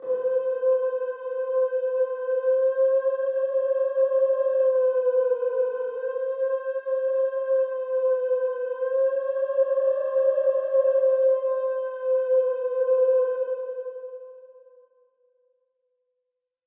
合成器无人机
描述：我用血清制作的小型合成无人机
标签： 实验 电子 声景 合成器 无人驾驶飞机 迷幻 空间
声道立体声